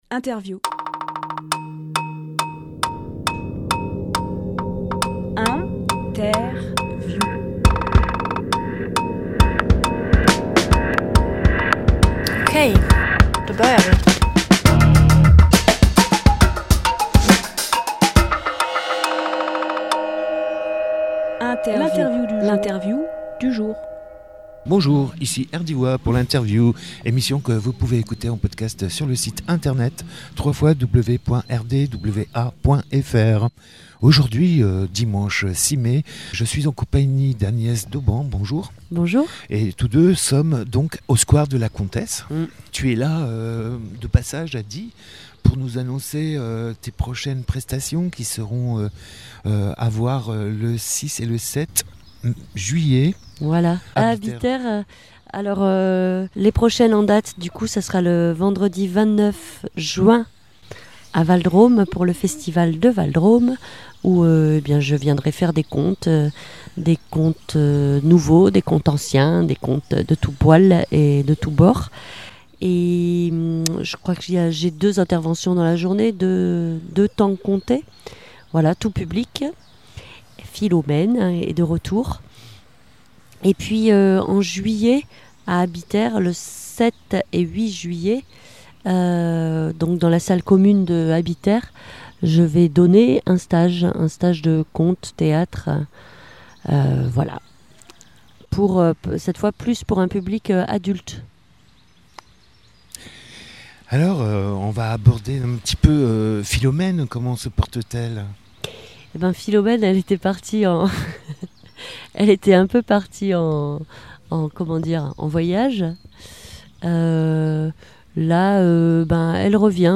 Emission - Interview
Lieu : Parc de la comtesse